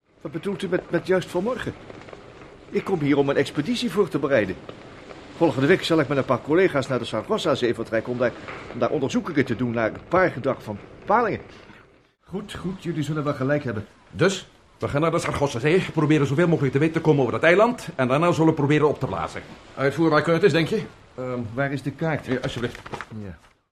In Prometheus XIII zijn zowel professor Curtis in deel 10 als ook Matt Meldon in deel 16 ervan overtuigt dat de bron van het kwaad te vinden is in de “SarGOSSAzee”. Omdat men het ook heeft over de trek van de paling, denk ik dat het toch SarGASSOzee moet zijn…